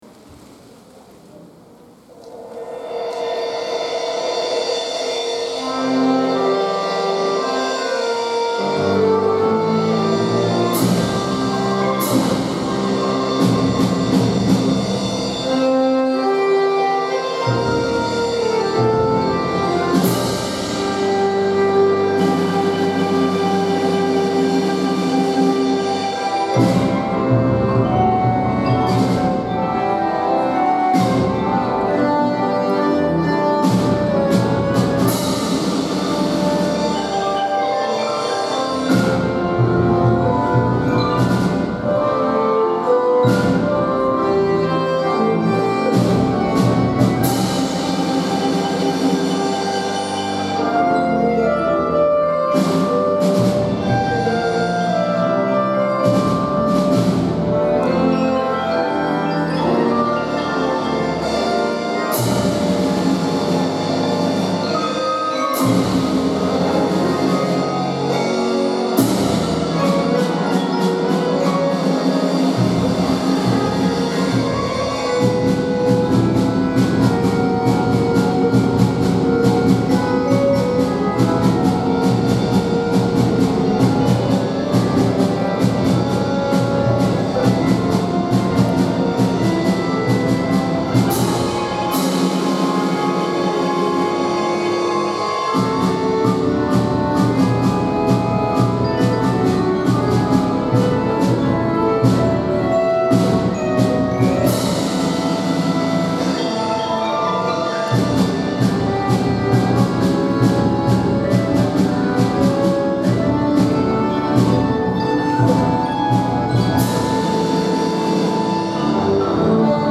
大空ありがとうコンサート
バック　トゥ　ザ　フューチャー」６年合奏（卒業演奏）です。
テンポが取りづらく、複雑な指使いや速いリズムにとても苦労しましたが、毎回の学習で友だちと学び会いながらこの卒業演奏をつくり上げてきました。
コンサート当日では、緊張しながらも今まで高めてきた自分の力を信じ、会場にいるみんなに自分たちのありがとうの想いを込めて演奏しました。